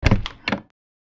Vintage-door-opening.mp3